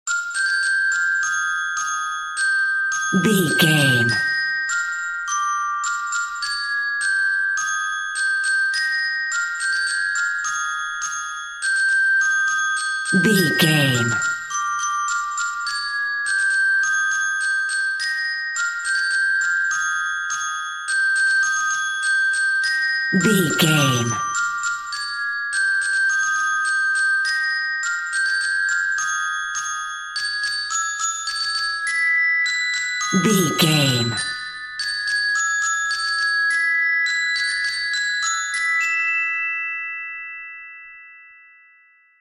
Ionian/Major
E♭
childrens music